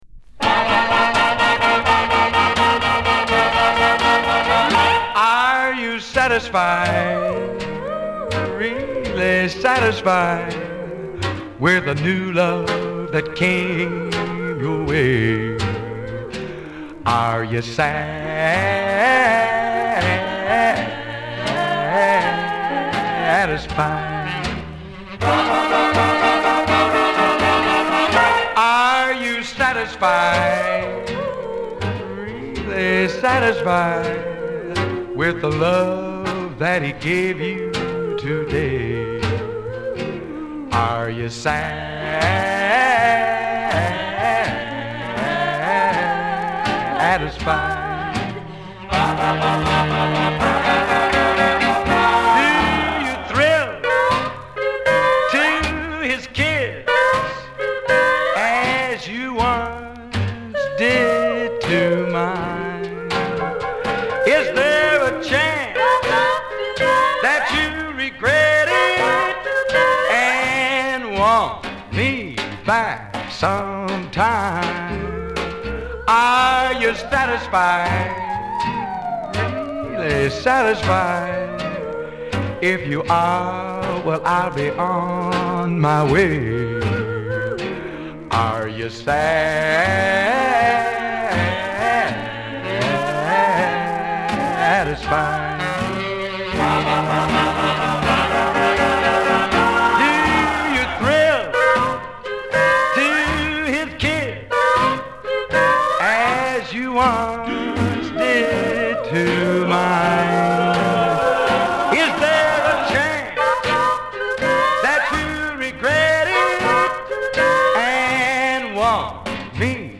ポピュラー・ミュージック黎明期から活動するカントリー〜ポップ〜ロックンロール・シンガー。
B面はバラードをじっくり聴かせる。